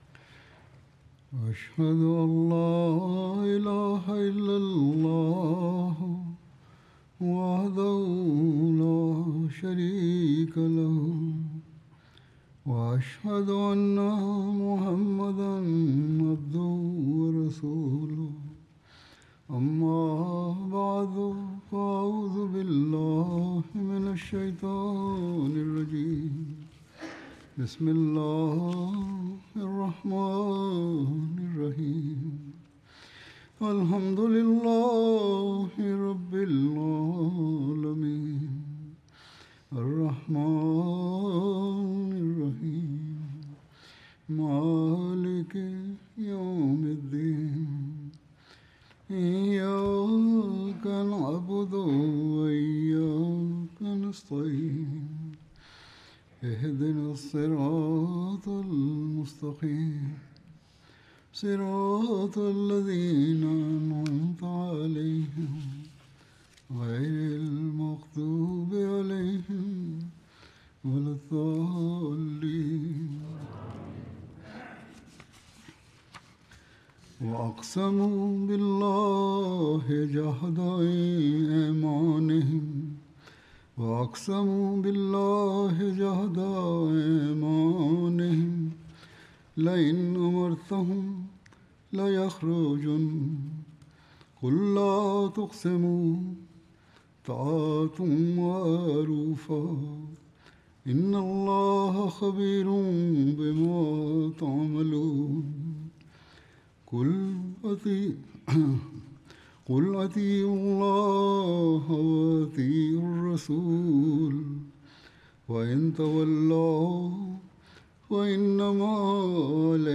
French translation of Friday Sermon delivered by Khalifa-tul-Masih on February 28th, 2025 (audio)